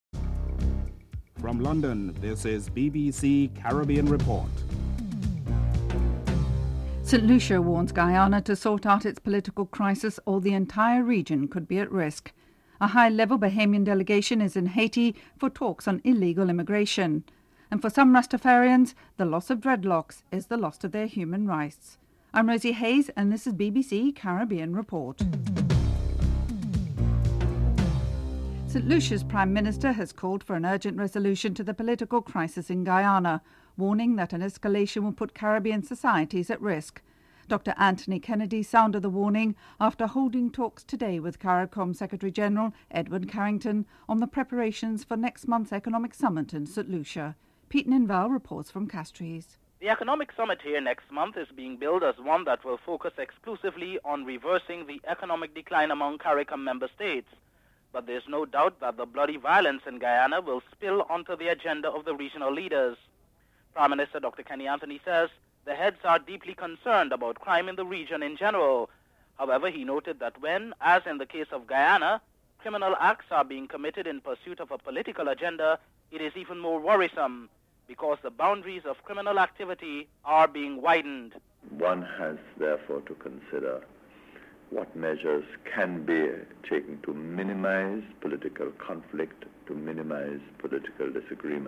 1. Headlines (00:00-00:27)